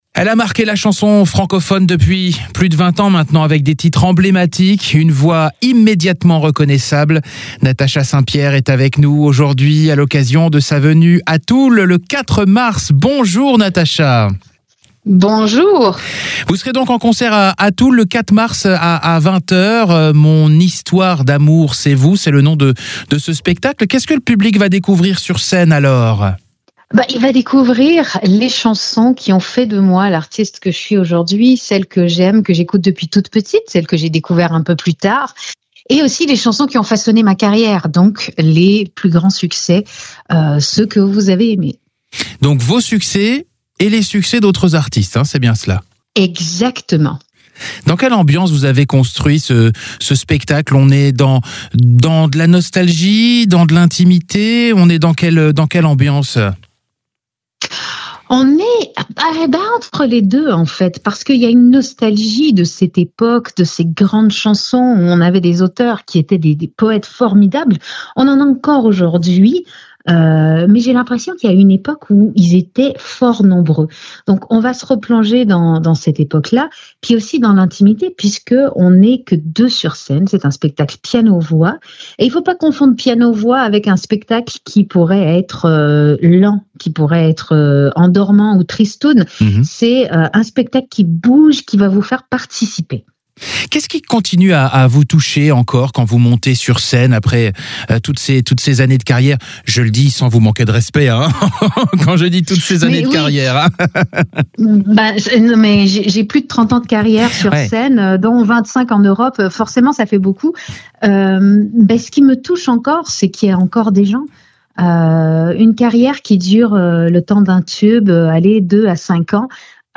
NATASHA ST-PIER EN INTERVIEW AVANT SON CONCERT A TOUL
Natasha St-Pier répond aux questions de MAGNUM LA RADIO.